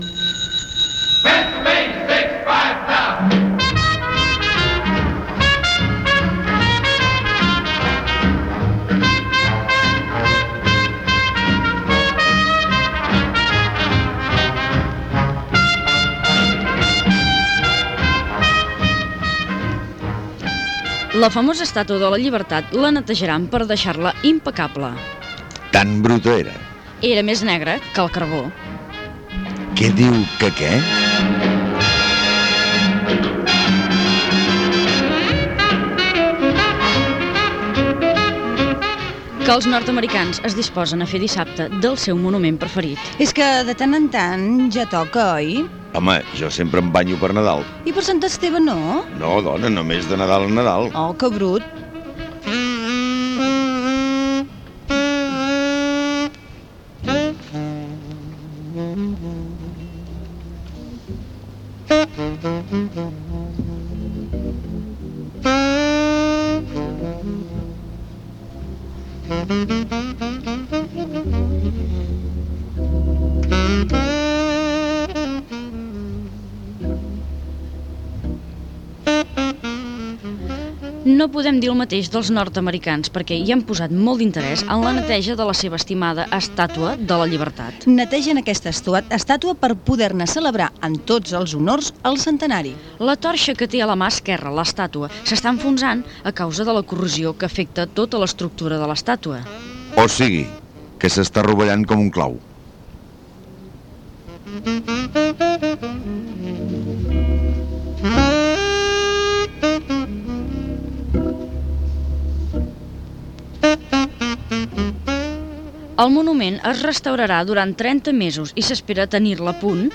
Netejaran l'estatua de la llibertat de Nova York, amb mutiu del seu centenari Gènere radiofònic Entreteniment